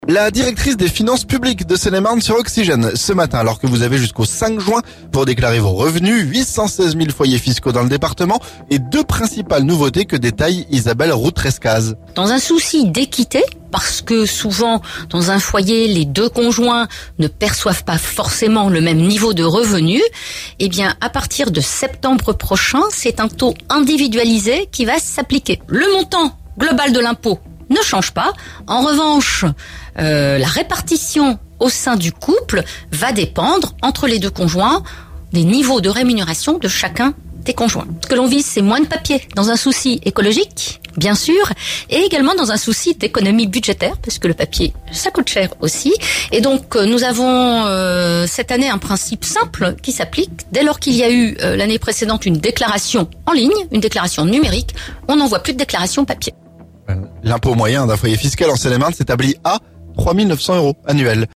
La directrice des finances publiques de Seine-et-Marne sur Oxygène ce lundi.